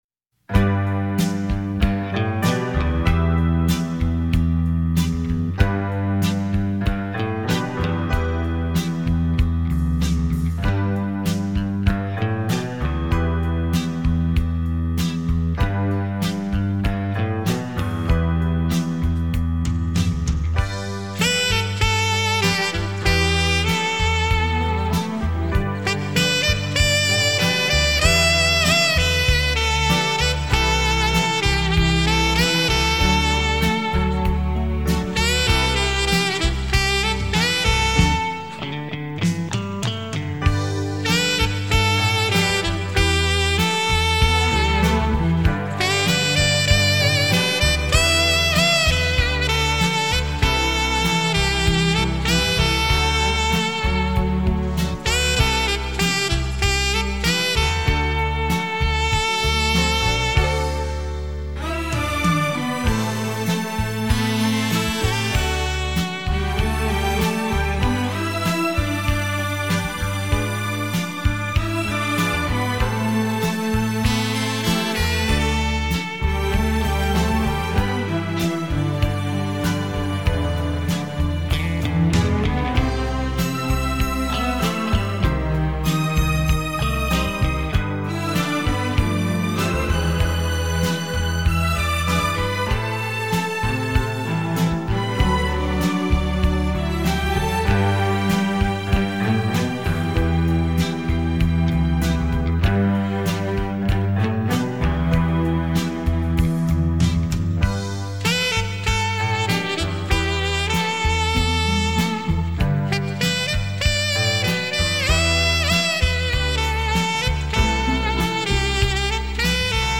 录音：亚絃录音室（台北）中国唱片公司（广州）
陶醉在萨克斯风JAZZ风味的时尚里，
兼具古典与流行的声音美学
质朴的萨克斯风与悠扬的弦乐共谱一场海峡两岸流行的爱恋